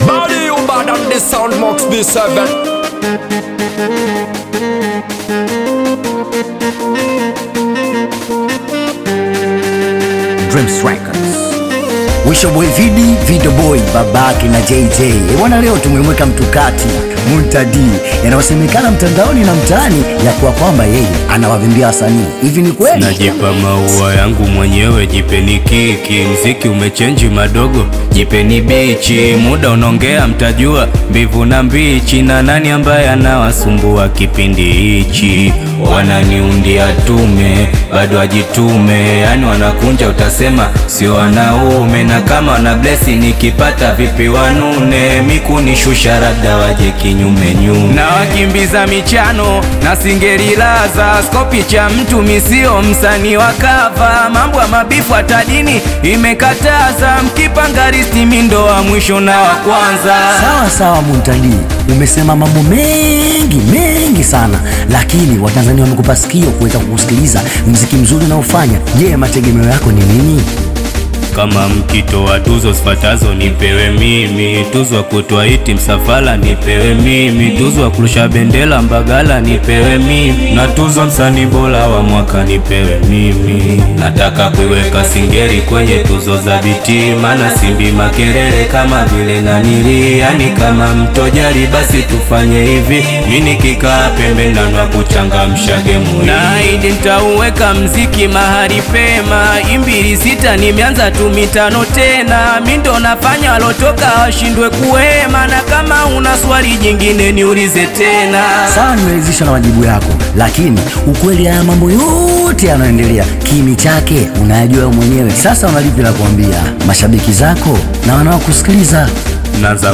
intense Tanzanian Singeli/Bongo Flava single
energetic delivery and authentic urban narrative